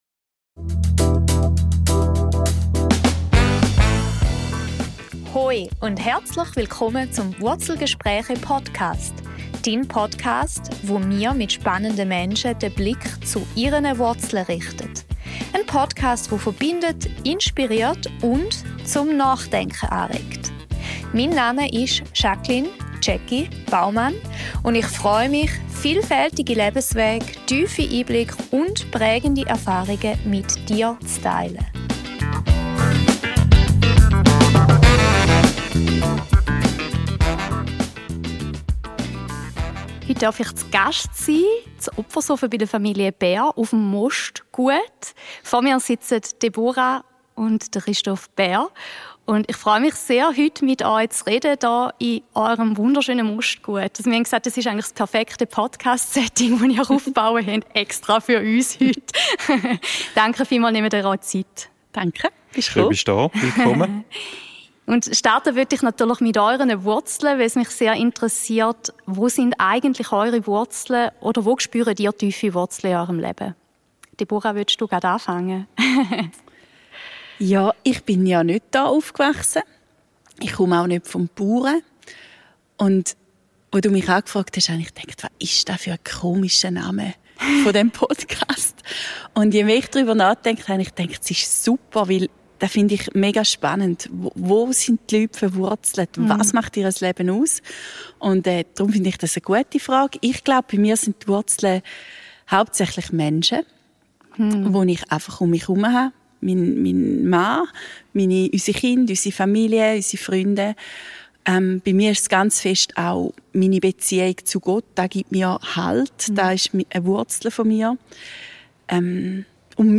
Ein Gespräch über Verbundenheit, Reifung und den Mut, gemeinsam zu wachsen.